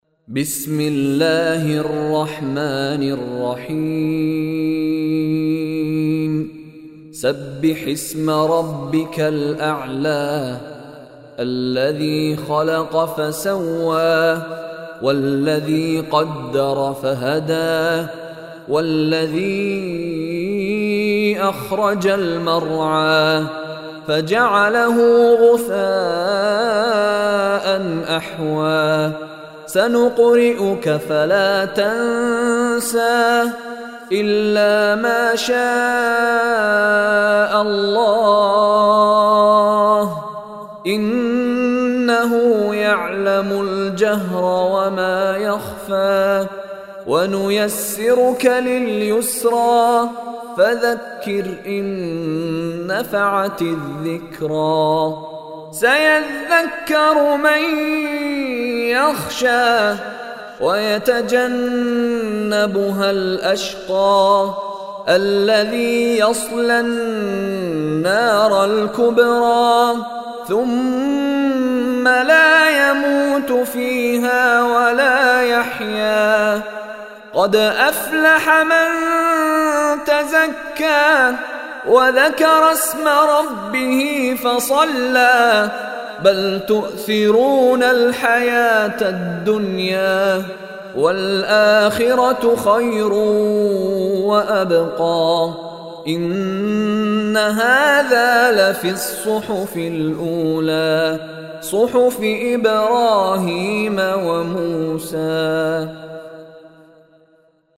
Surah Al-Ala Recitation by Sheikh Mishary Rashid
Surah Al-Ala is 87th chapter of Holy Quran. Listen online and download mp3 arabic recitation/ tilawat of Surah Al-Ala in the voice of Sheikh Mishary Rashid Alafasy.